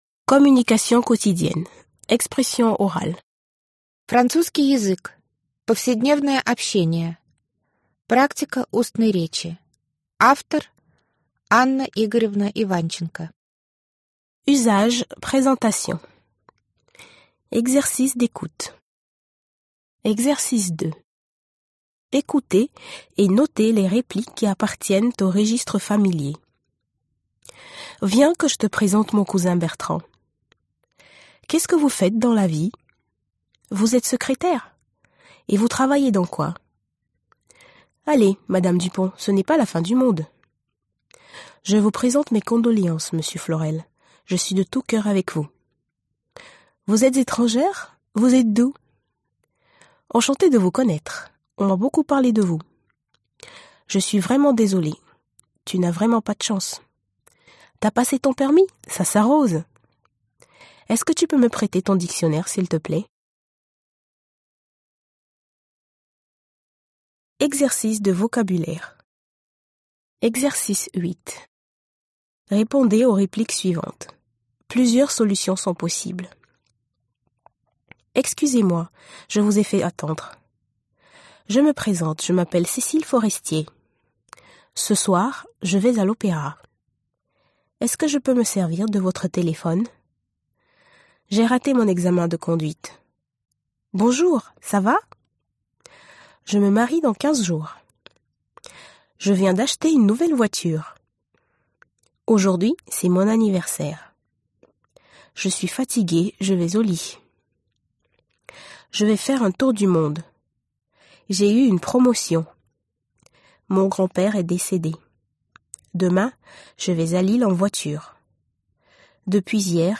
Аудиокнига Французский язык. Повседневное общение. Практика устной речи | Библиотека аудиокниг